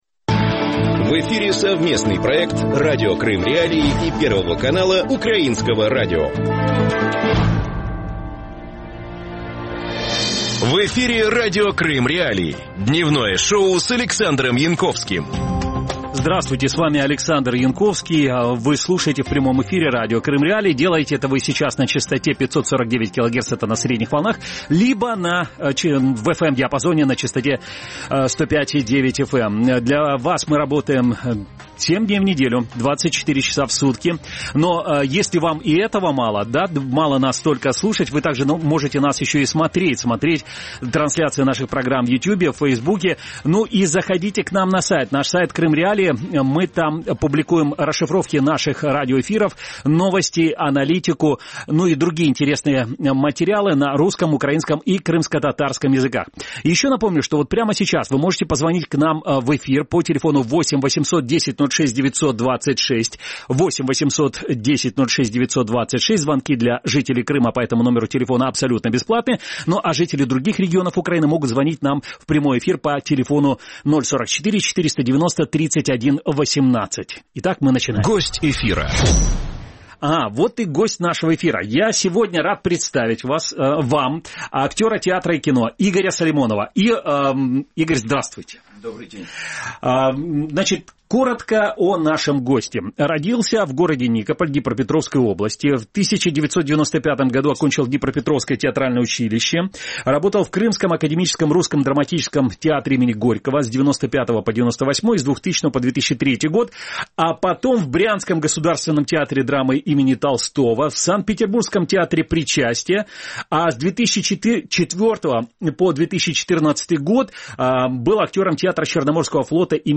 Кино и «немец». Интервью
Об этом – в «Дневном шоу» в эфире Радио Крым.Реалии с 12:10 до 12:40.